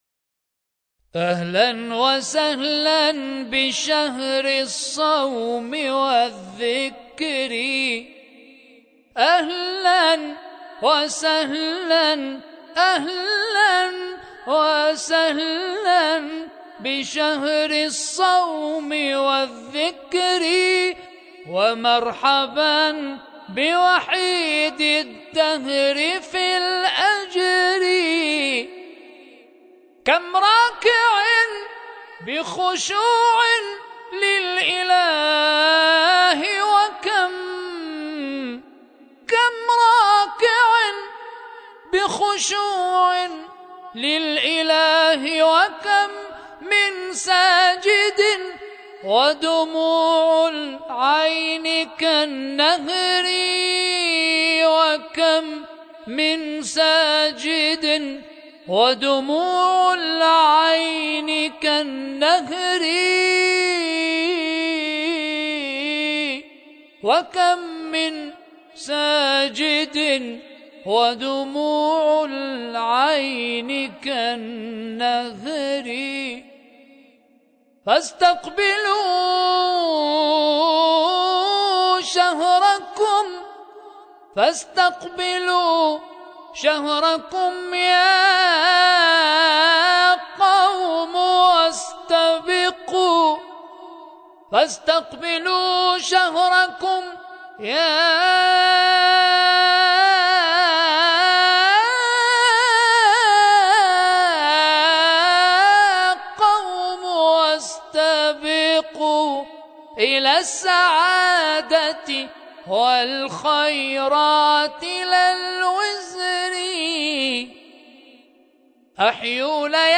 اهلاً وسهلاً بشهر الصيام ـ ابتهالات